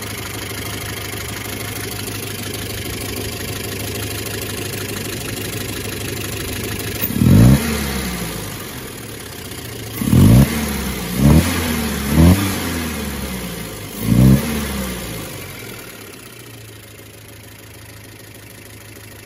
5. Шум работающего двигателя автомобиля Волга
gaz24-rabota-dvig.mp3